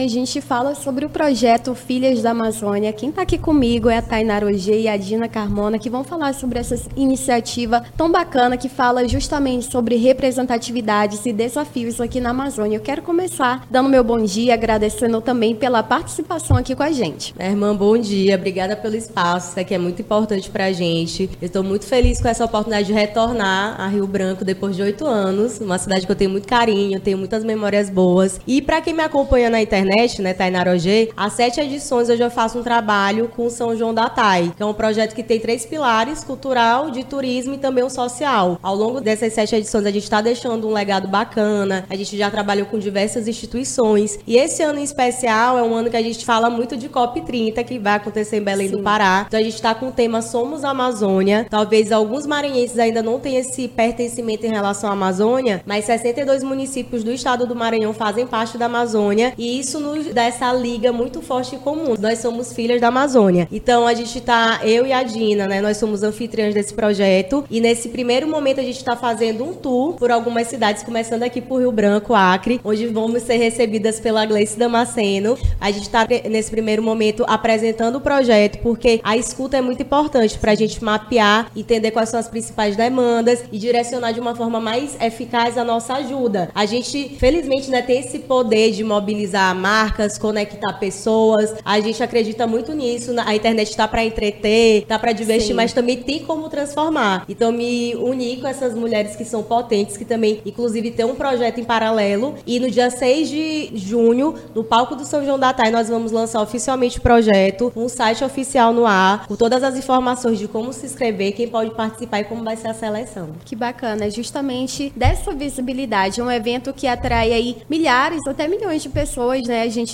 Nome do Artista - CENSURA - ENTREVISTA FILHAS DA AMAZÔNIA (08-05-25).mp3